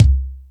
Lotsa Kicks(44).wav